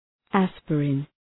Προφορά
{‘æspərın}